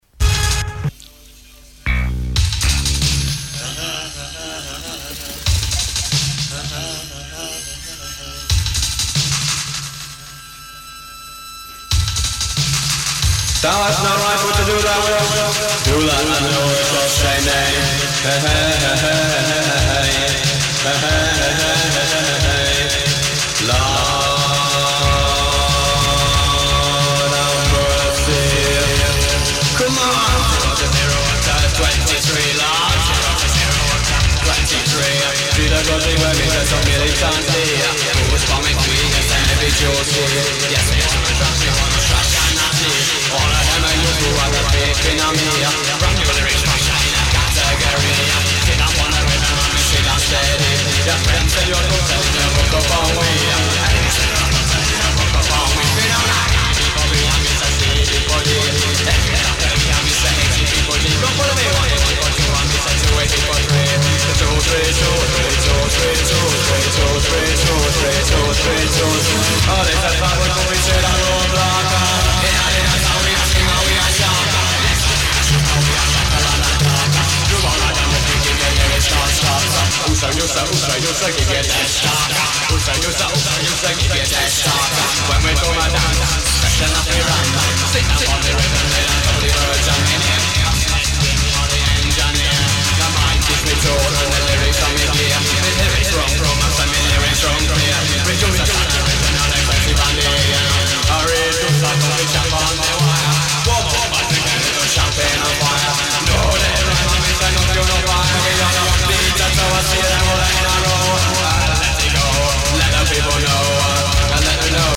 カヴァーヴァージョン